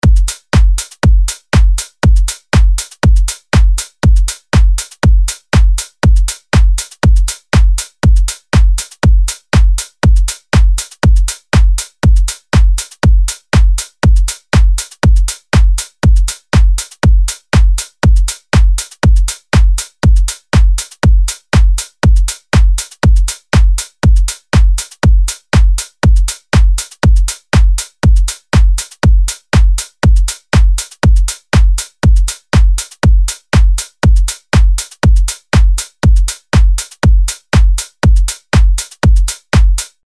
LP 206 – DRUM LOOP – HOUSE – 120BPM